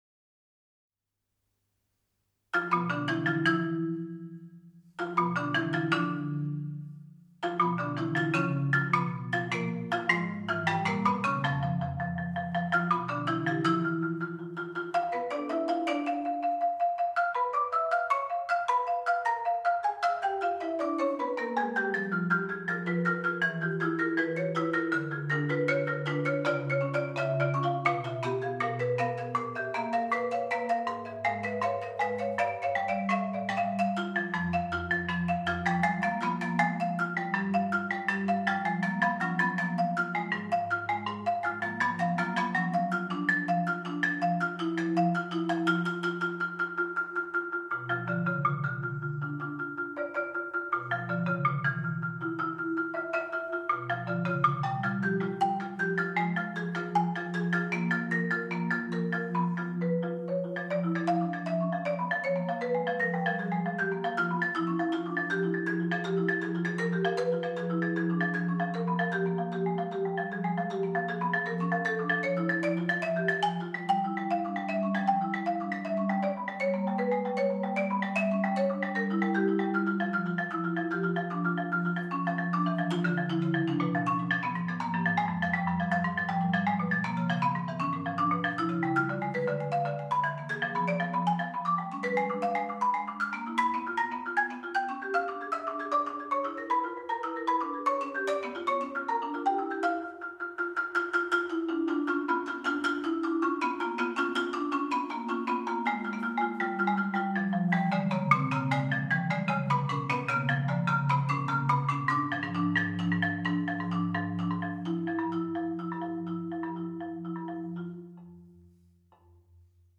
Genre: Marimba (4-mallet)